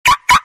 Car sound ringtone iphone ringtone free download